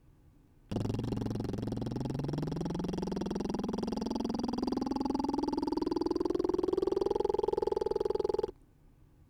１. リップバブルで地声～裏声を連結してみる
音量注意！
この練習のポイントは、地声から裏声に移り変わっていく際にリップバブルの音の質が変わらないようにめちゃくちゃ慎重にグラデーションさせることです。